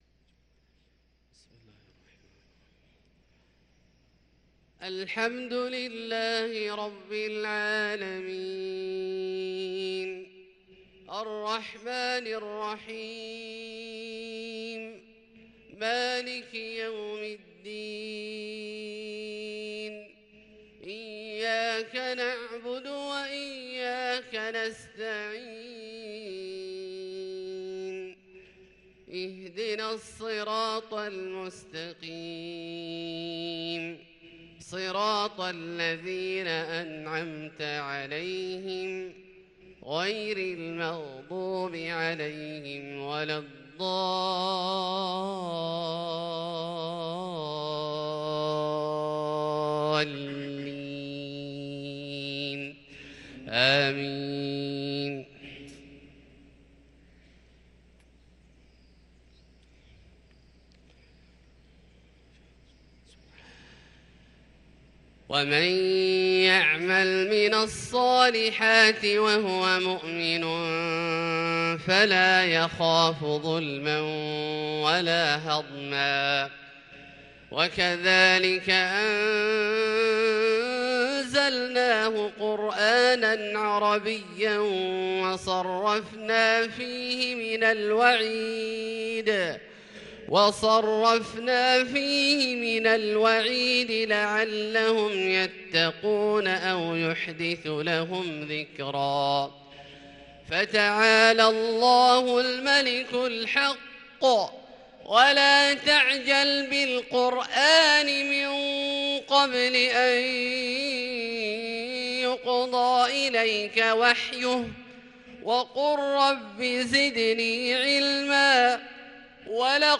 صلاة الفجر للقارئ عبدالله الجهني 28 جمادي الأول 1444 هـ